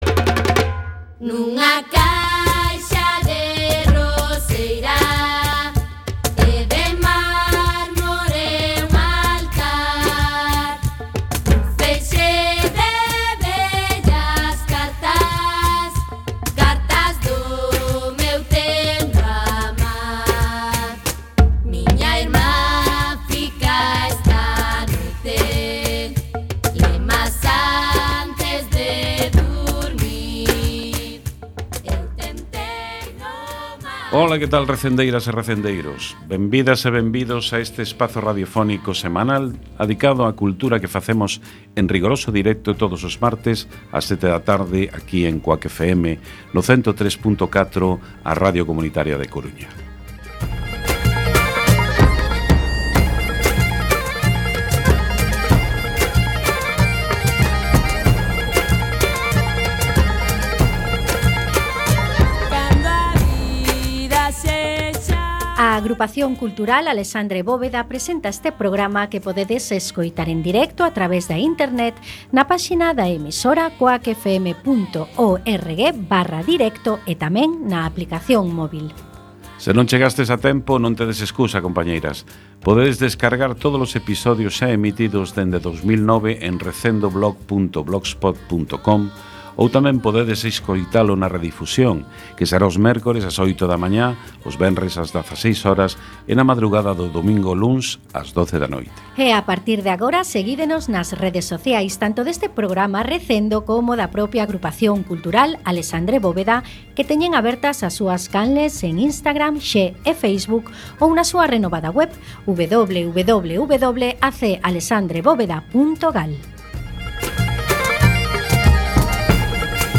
17x8 Entrevista Sobre A Casa Cornide